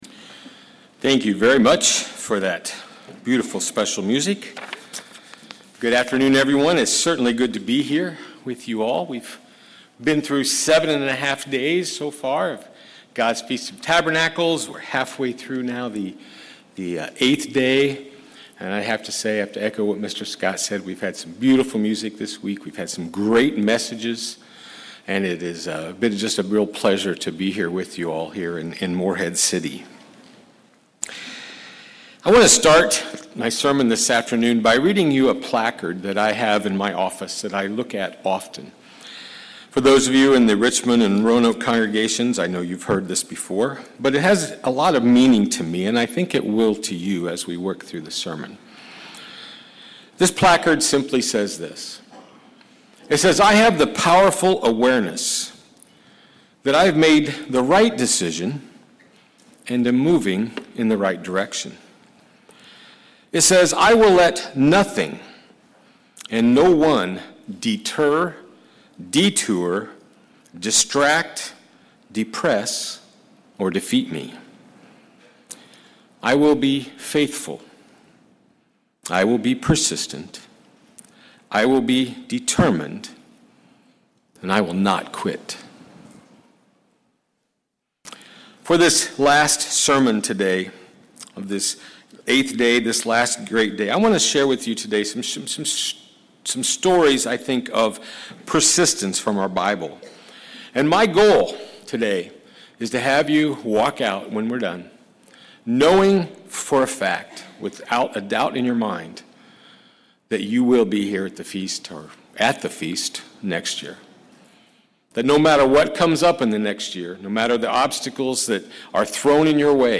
Eighth Day sermon Studying the bible?